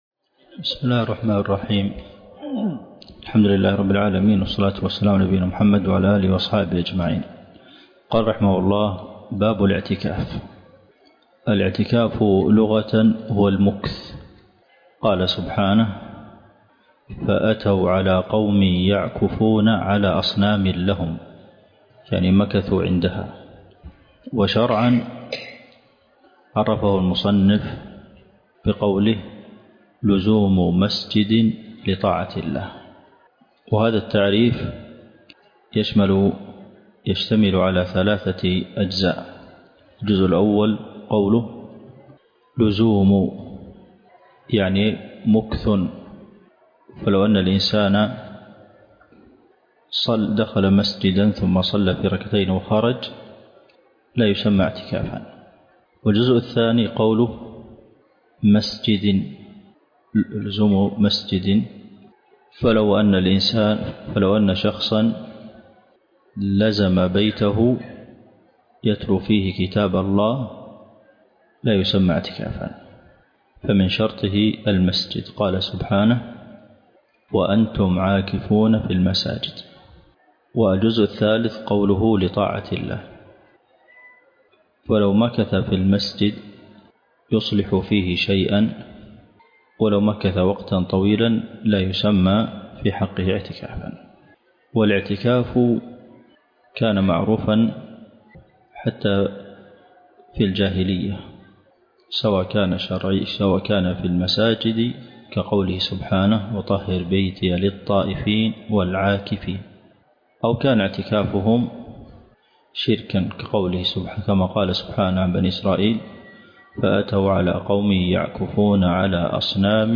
الدرس (17) شرح زاد المستقنع دورة في فقه الصيام - الشيخ عبد المحسن القاسم